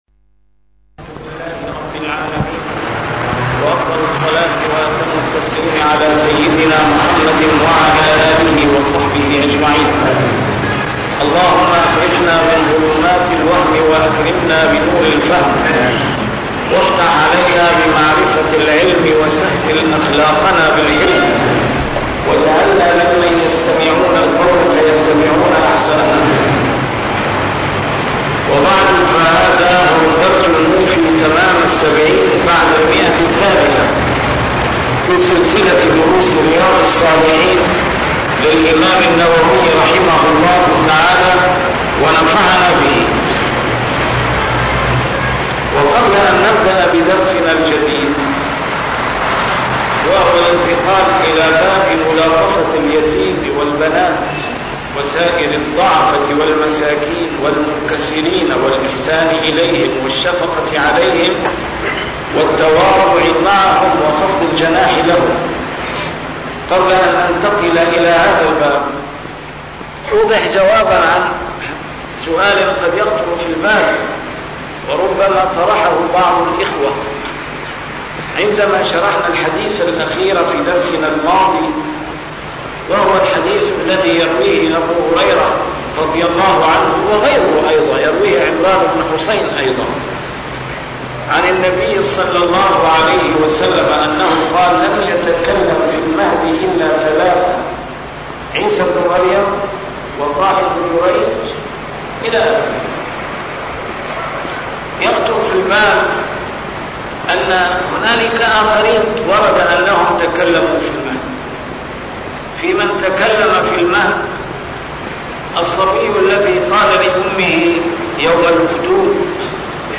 A MARTYR SCHOLAR: IMAM MUHAMMAD SAEED RAMADAN AL-BOUTI - الدروس العلمية - شرح كتاب رياض الصالحين - 370- شرح رياض الصالحين: ملاطفة اليتيم والبنات